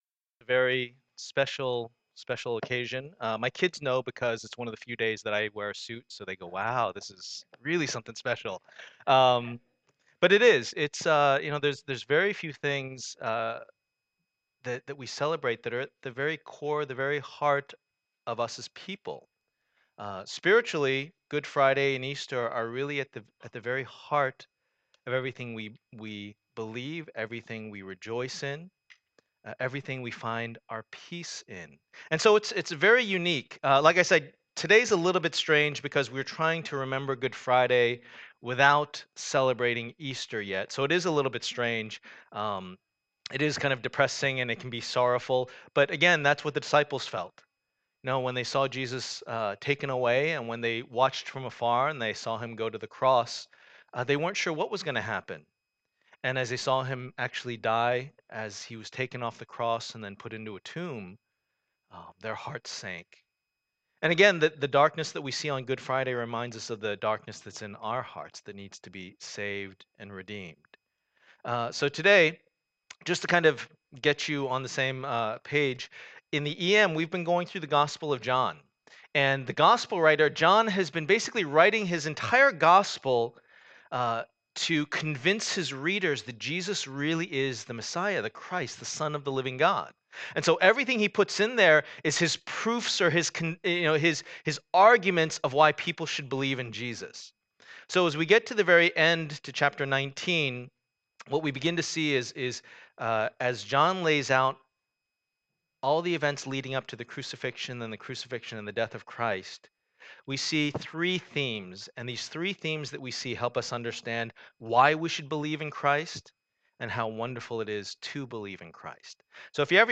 Passage: John 19:16-30 Service Type: Special Event